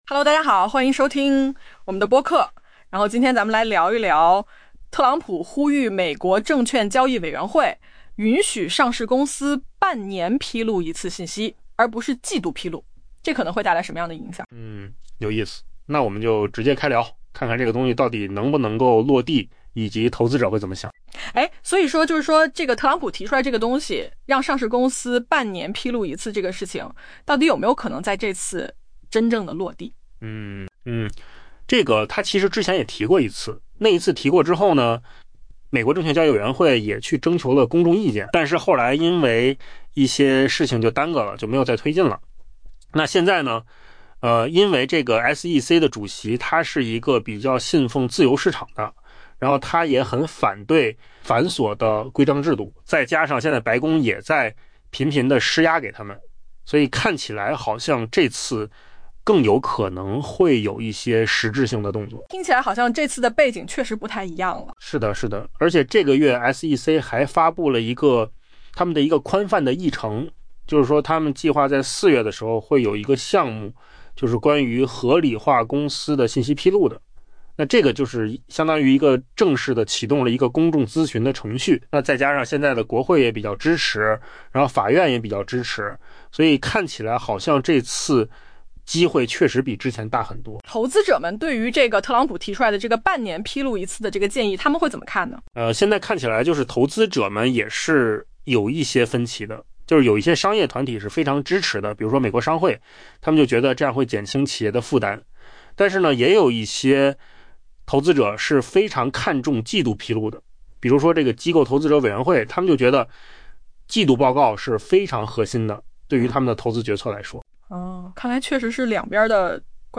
AI 播客：换个方式听新闻 下载 mp3 音频由扣子空间生成 特朗普周一呼吁 SEC 允许在美国上市的公司每六个月发布一次定期信息披露，而不是要求提交季度报告。